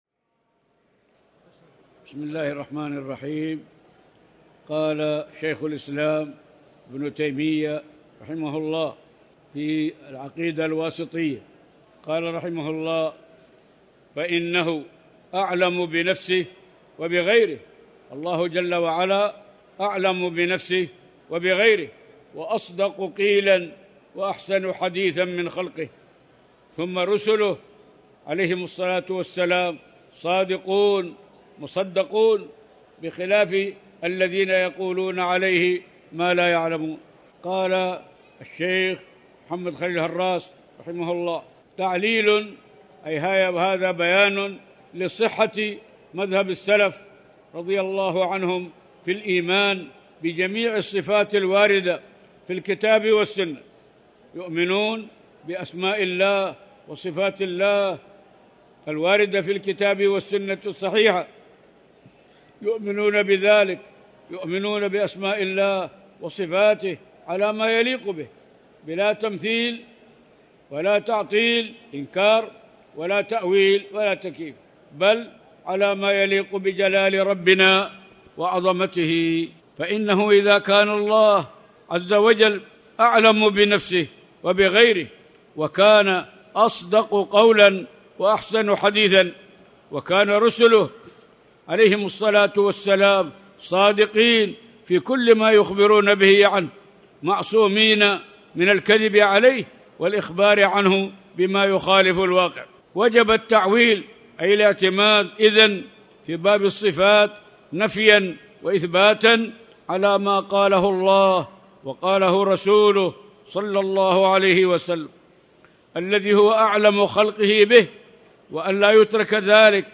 تاريخ النشر ٢ صفر ١٤٣٩ هـ المكان: المسجد الحرام الشيخ